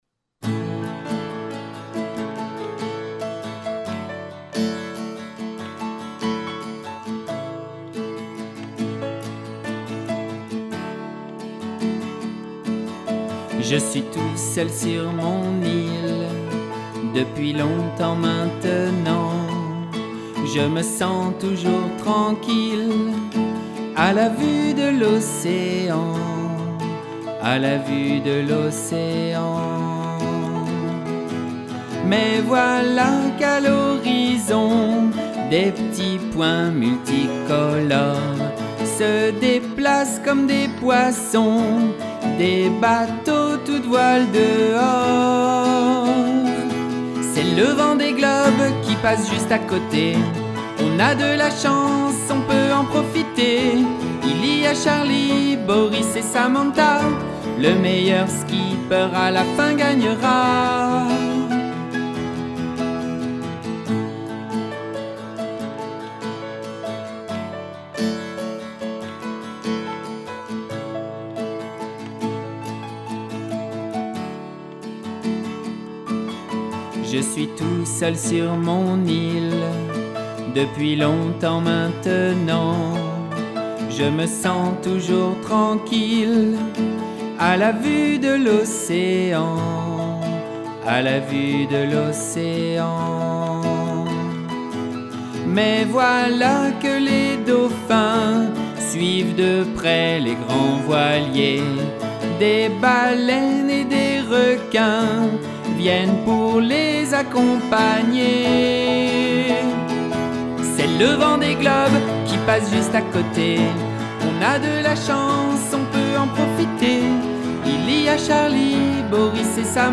Chanson pour enfants (grands)